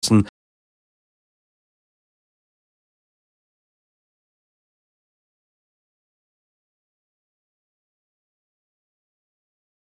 Nachrichten - 13.02.2025